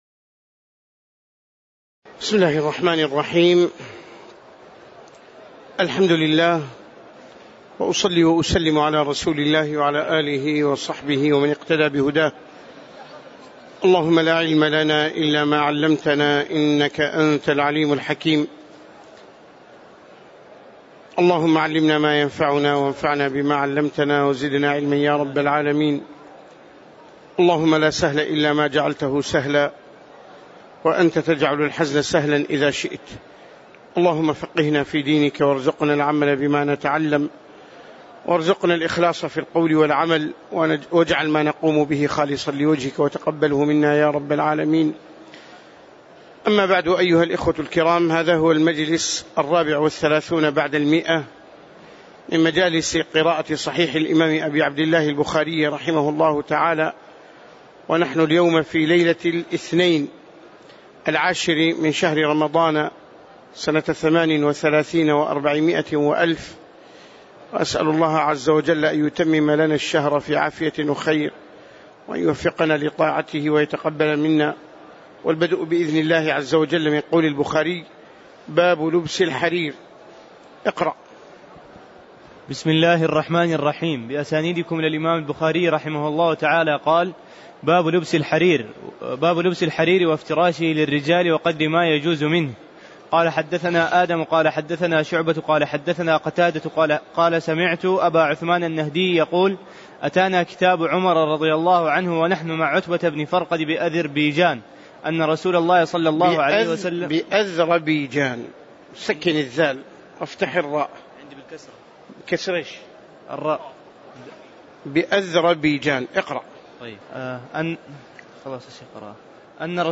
تاريخ النشر ٩ رمضان ١٤٣٨ هـ المكان: المسجد النبوي الشيخ